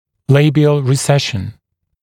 [‘leɪbɪəl rɪ’seʃn][‘лэйбиэл ри’сэшн]рецессия десны во фронтальном сегменте